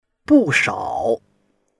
bu4shao3.mp3